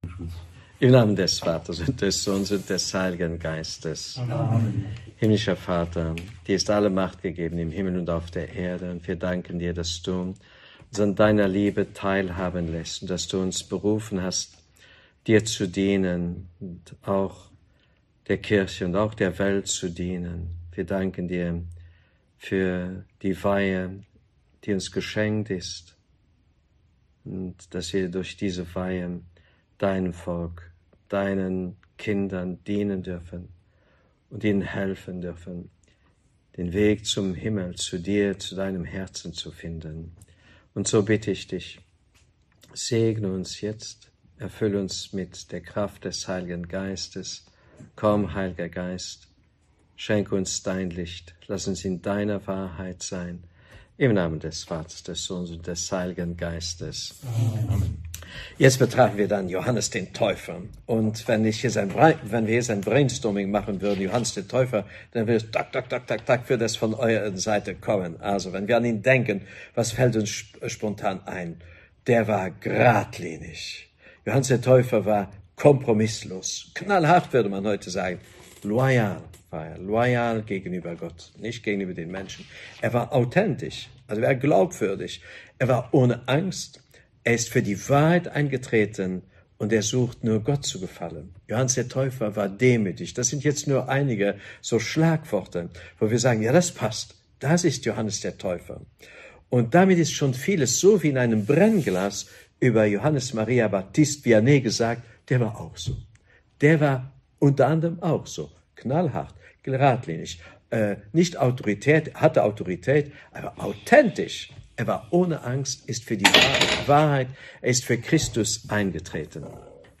Katholische Predigten & Vorträge Podcast